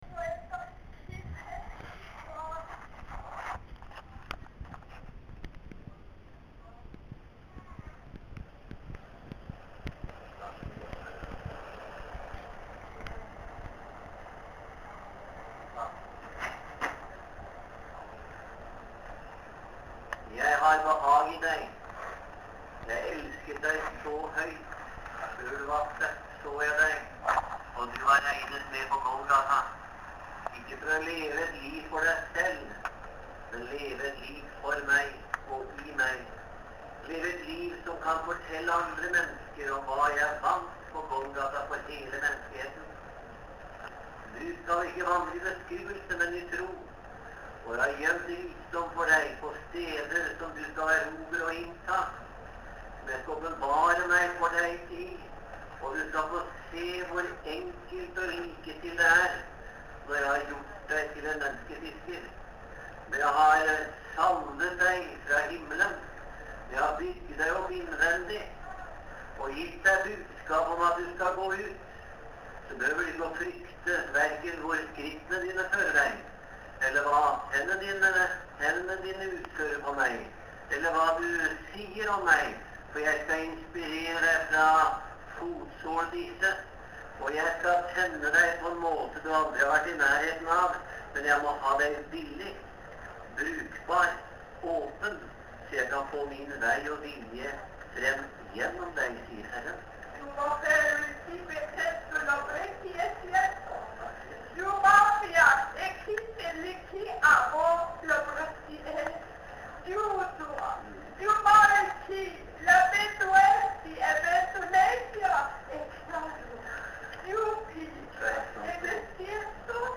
Møte i den Frie Evangeliske Forsamling i Møllergata 40 i Oslo, laurdag 12.2.2011.